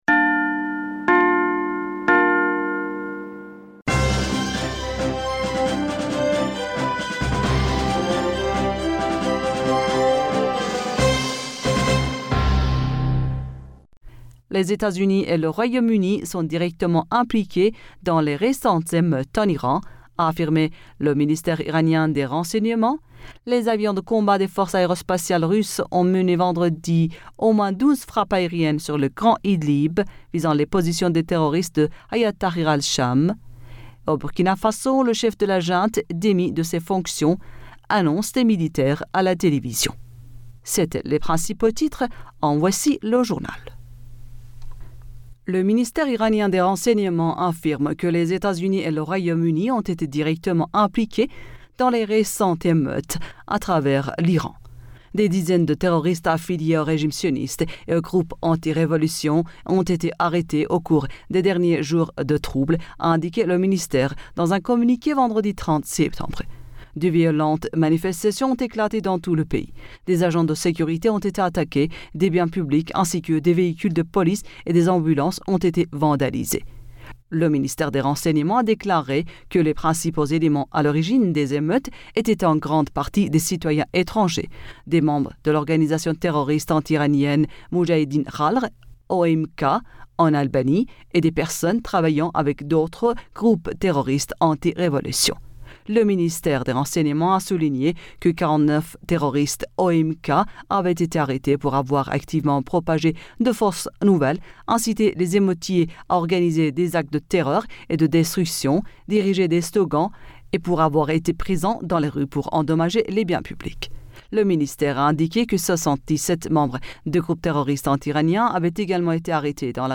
Bulletin d'information Du 01 Octobre